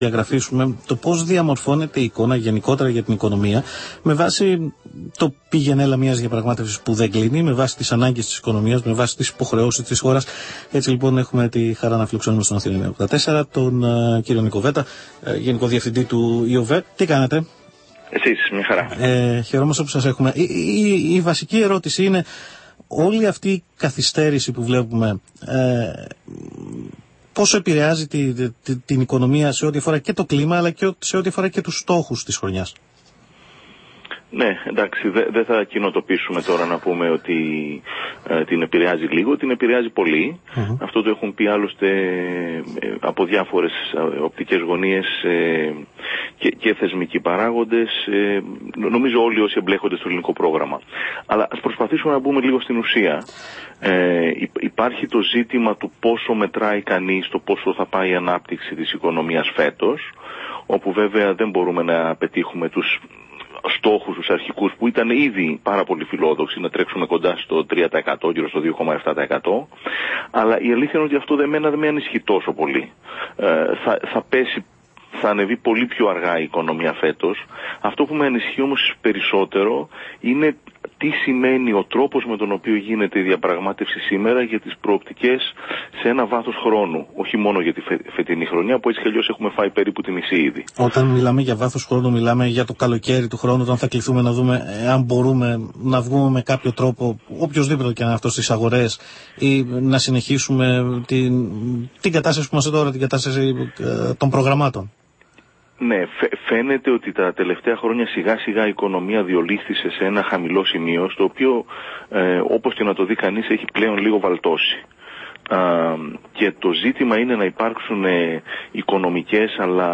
Συνέντευξη στον Αθήνα 9,84